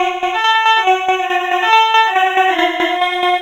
Index of /90_sSampleCDs/Sample Magic - Transmission-X/Transmission-X/transx loops - 140bpm